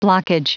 Prononciation du mot blockage en anglais (fichier audio)
Prononciation du mot : blockage